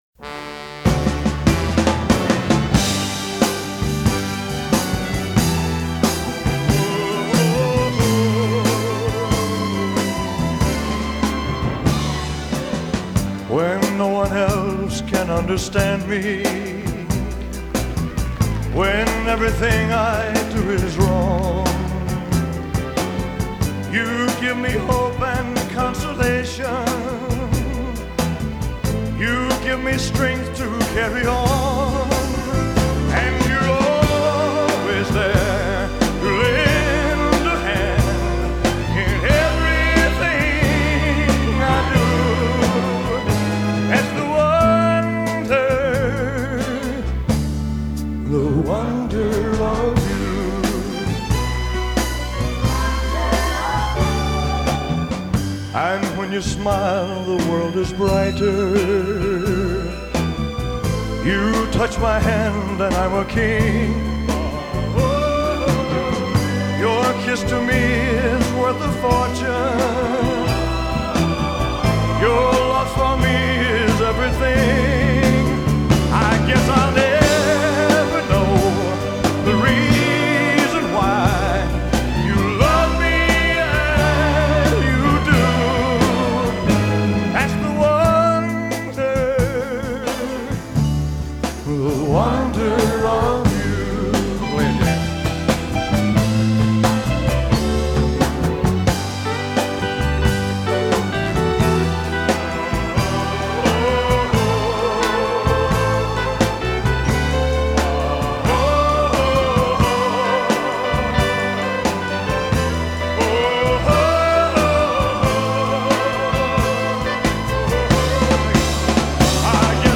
No te pierdas la recomendación musical que el comunicador ha preparado para ti este miércoles